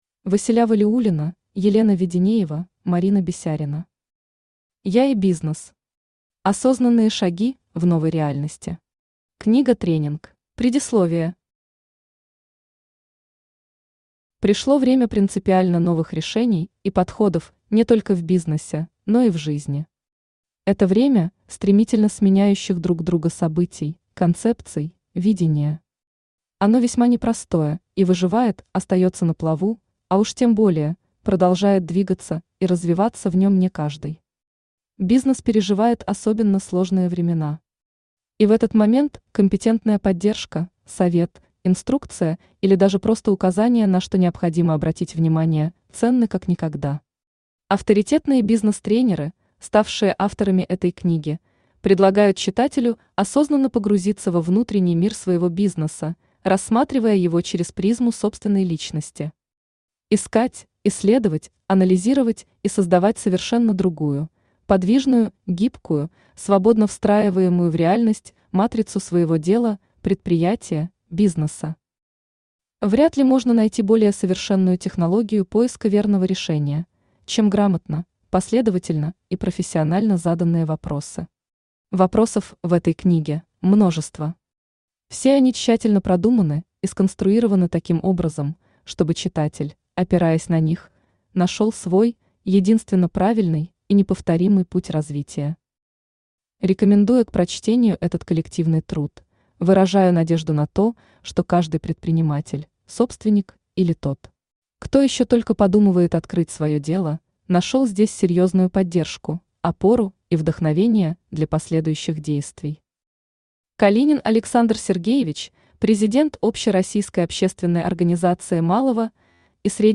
Книга-тренинг Автор Василя Валиуллина Читает аудиокнигу Авточтец ЛитРес.